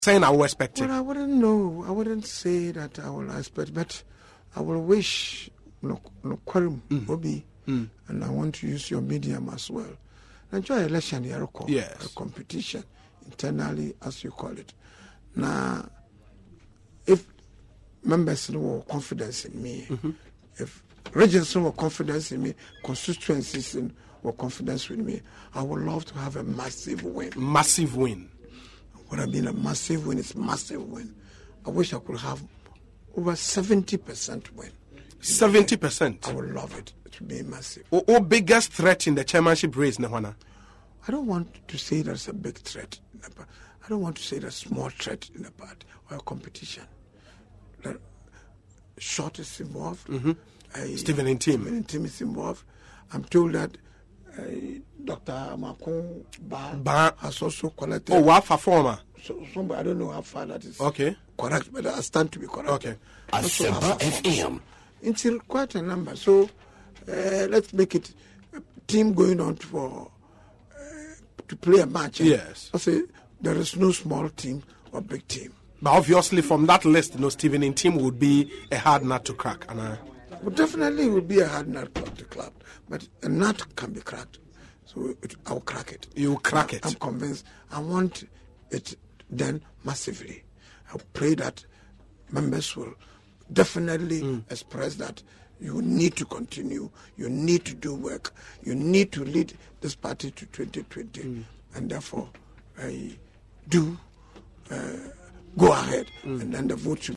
The man who has been calling on delegates within the ruling party to maintain him as the chairman of the party said on Asempa FM’s Ekosii Sen, Friday, that he arrived at that figure after a vigorous campaign.